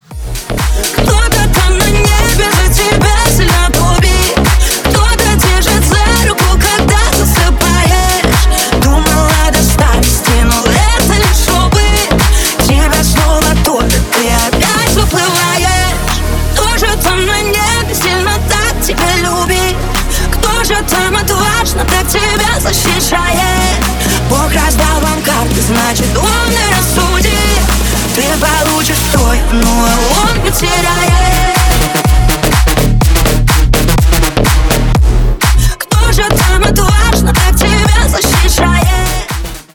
ремиксы
поп